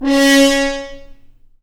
Index of /90_sSampleCDs/Roland L-CDX-03 Disk 2/BRS_F.Horns FX+/BRS_FHns Mutes